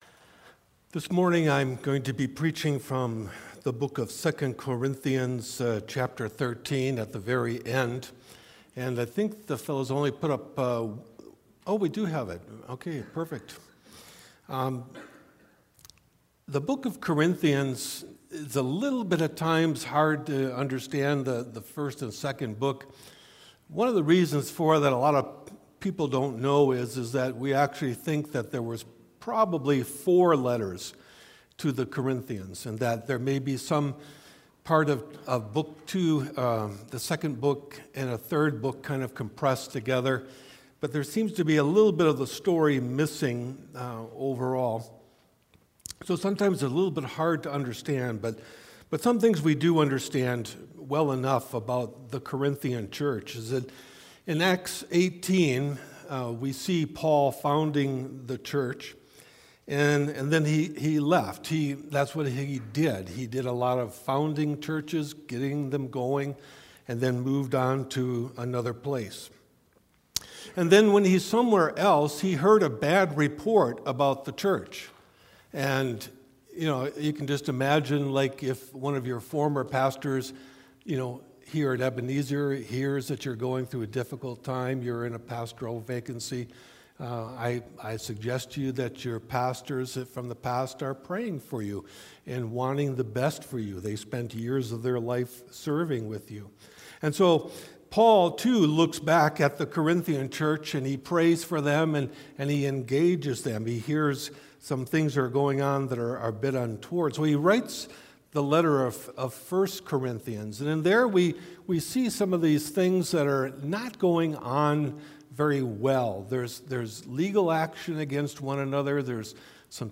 Guest Speaker
Stand Alone Sermons